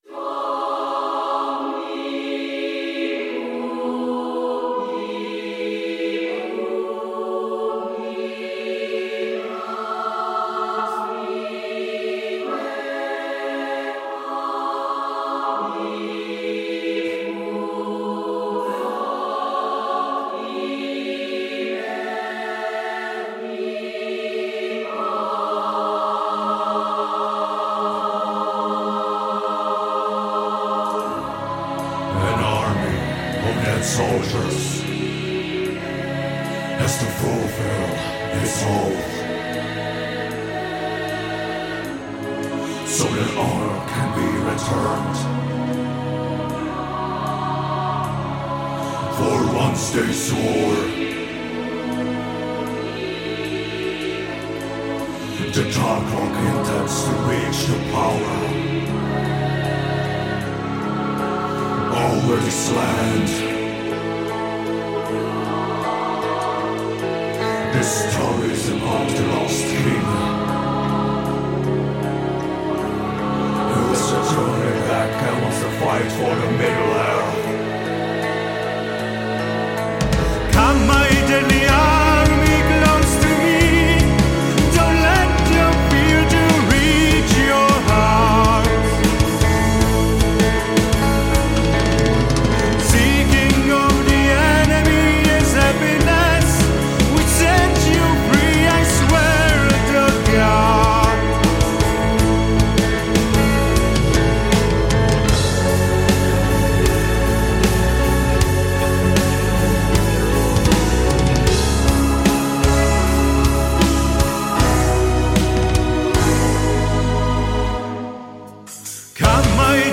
Pôsobí to na mňa mohutne temne filmovo seversky.